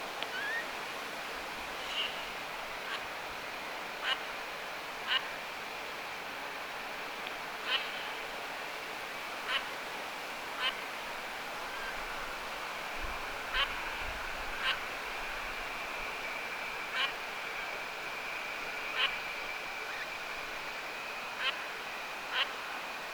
ilmeisesti tavi,
pari kilpikanan poikasen pientä ääntä
ilmeisesti_tavi_pari_kilpikanan_poikasen_pienta_aanta.mp3